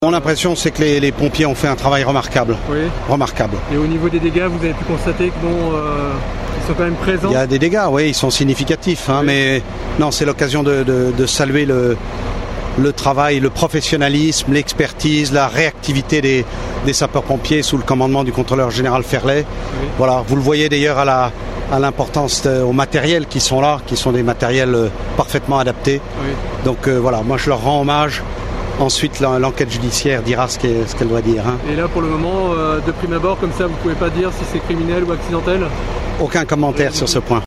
REACTION-PREFET-Claude-d-Harcourt.mp3